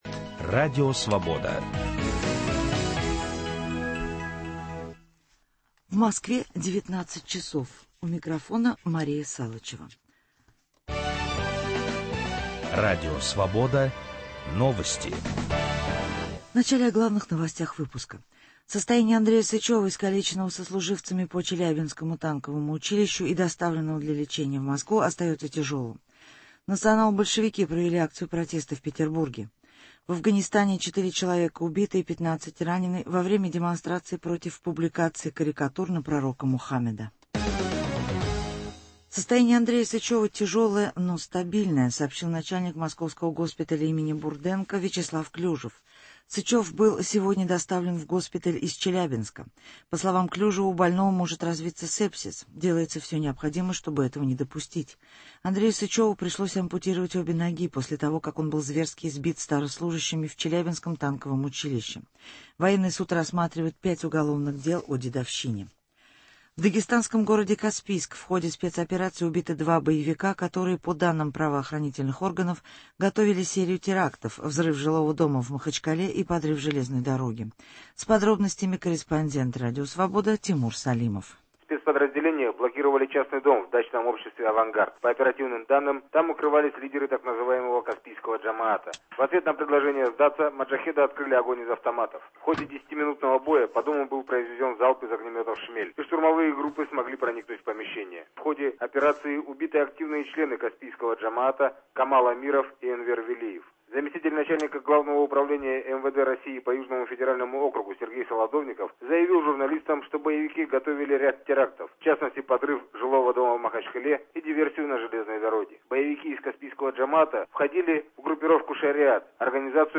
беседует с лидером партии «Родина» Дмитрием Рогозиным